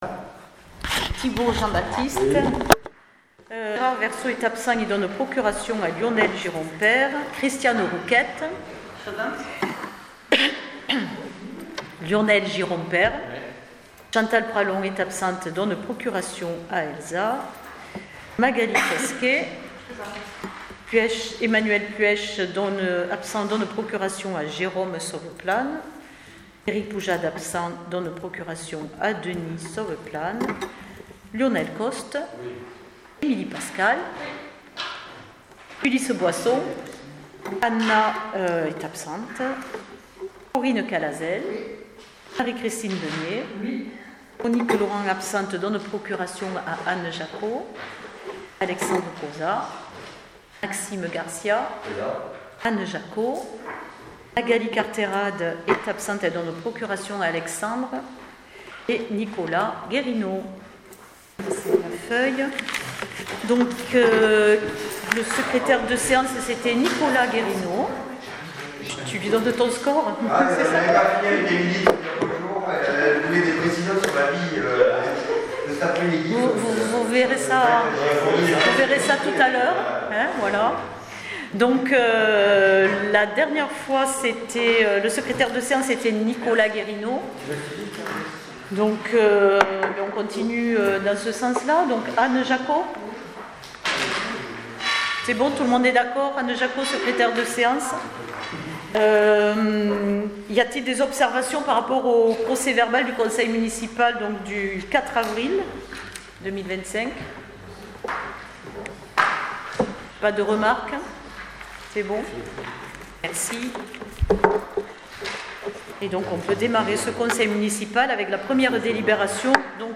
PROCÈS VERBAL DU CONSEIL MUNICIPAL DU 12 MAI 2025